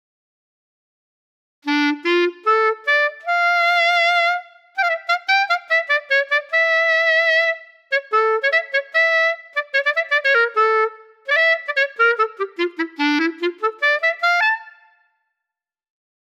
מצורף קטע קצר שניגנתי מזיכרון, ככה הוא מתחיל....
קבצים מצורפים Klezmer.wav 2.7 MB · צפיות: 26